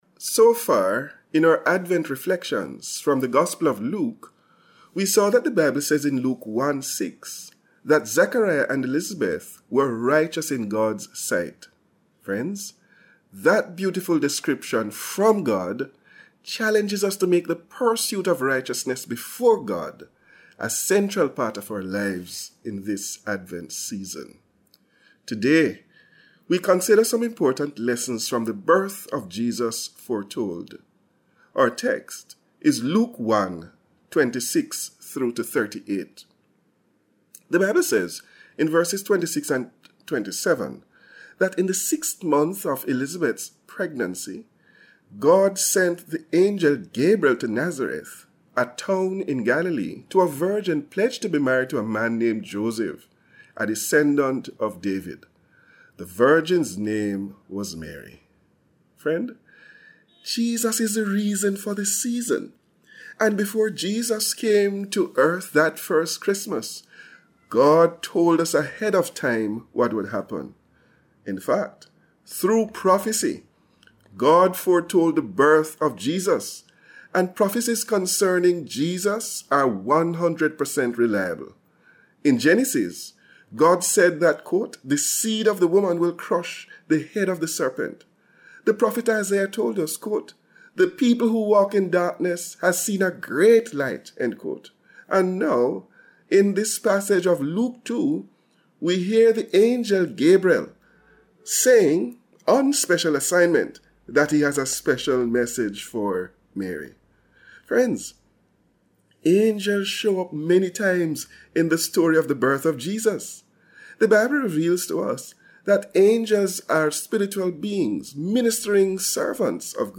So far, in our Advent reflections from the Gospel of Luke, we have seen that Zachariah and Elizabeth were righteous in God’s sight, as noted in Luke 1:6.